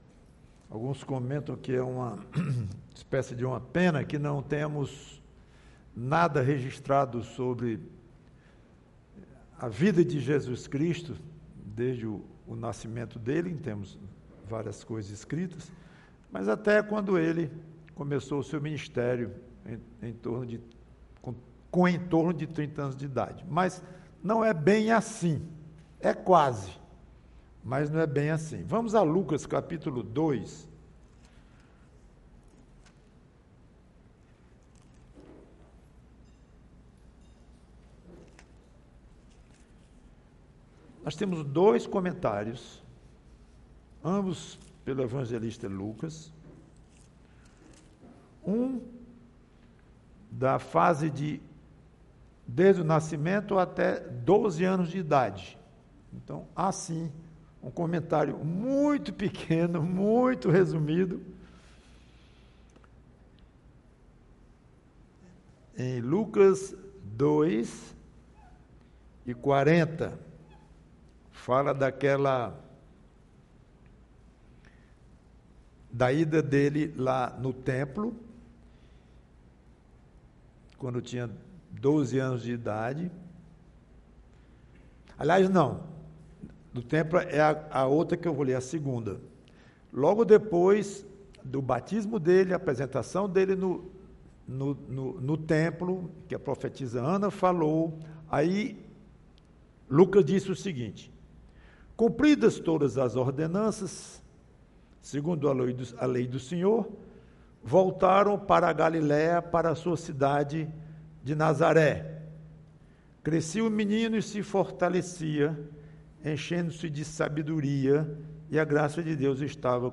PREGAÇÃO Jesus homem: crescendo... sempre crescendo!